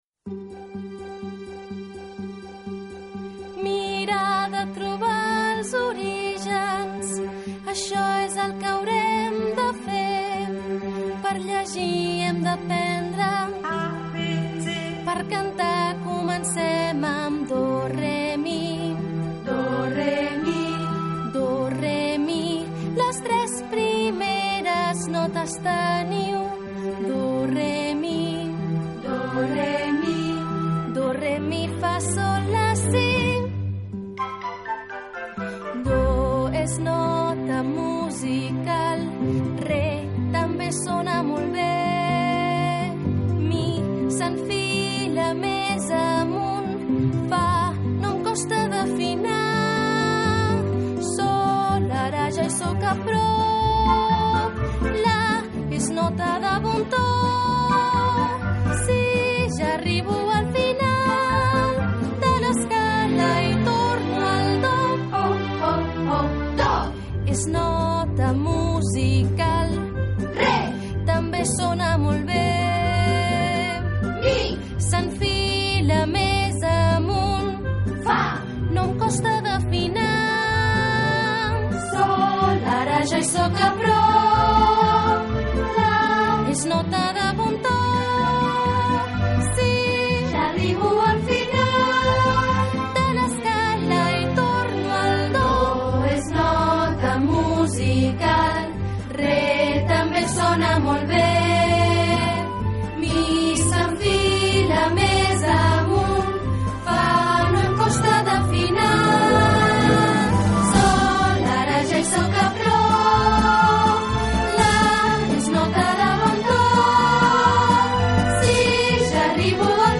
Alumnat de 4t, aquí us deixo la cançó que hem practicat a classe de música.